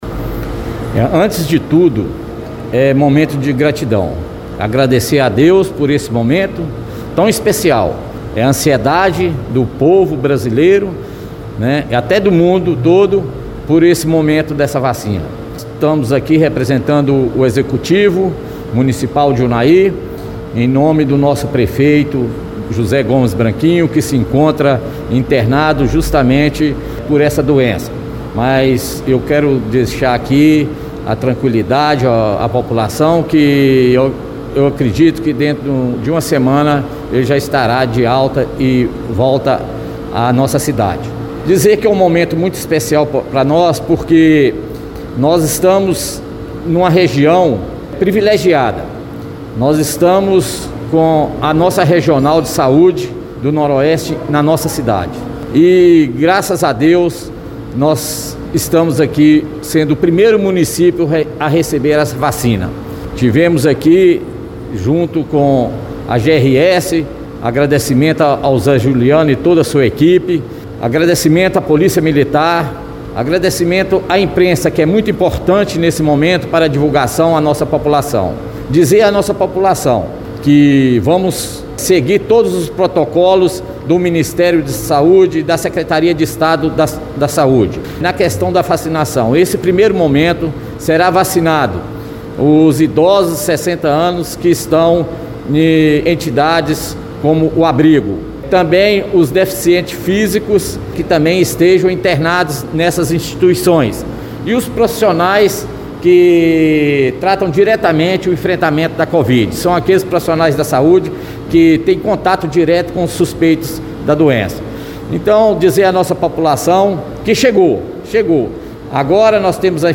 Representando o executivo, o prefeito em exercício, Waldir Novais, também participou do recebimento da cota de vacinas destinadas a Unaí. Aos microfones da Radio Veredas, ele comentou que era um momento de gratidão, e lembrou que a ausência do prefeito Jose Gomes Branquinho se dava justamente pelo fato de o mesmo ter sido acometido da infecção provocada pela covid.